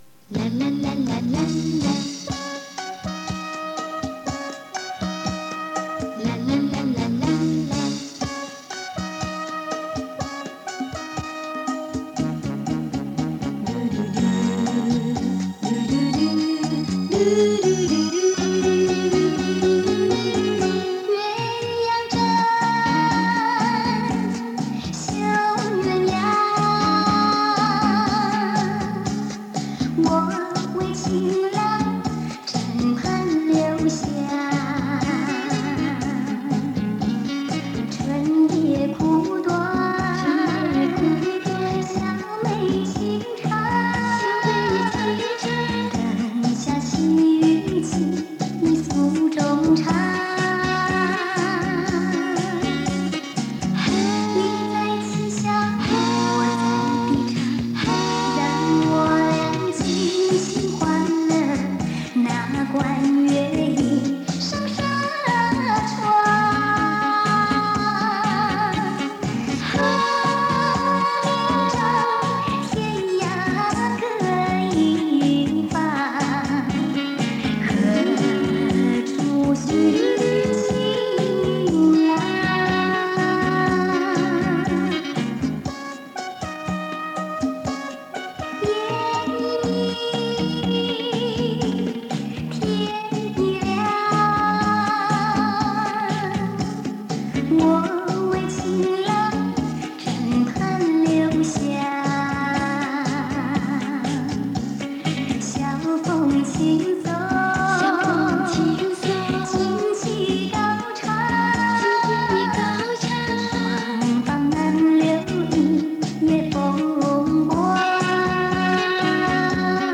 磁带数字化：2022-05-31